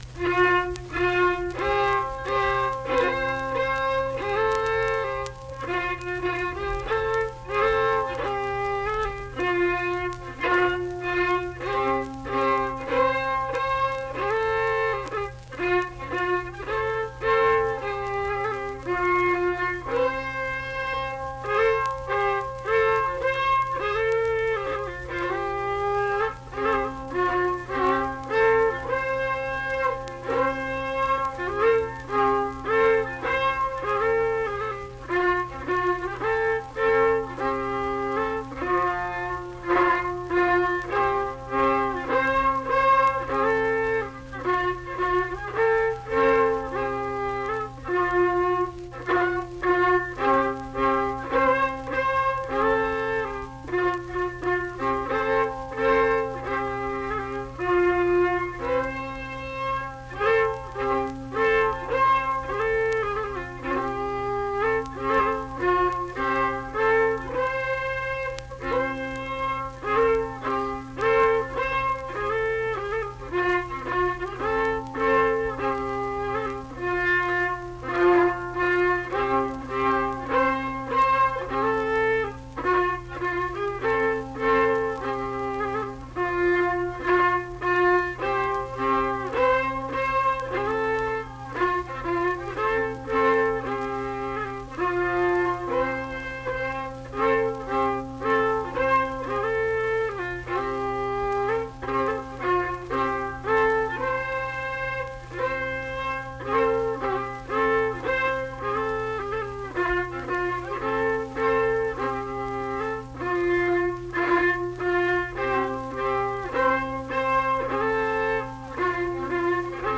Unaccompanied fiddle and vocal music performance
Instrumental Music, Hymns and Spiritual Music
Fiddle
Braxton County (W. Va.), Flatwoods (Braxton County, W. Va.)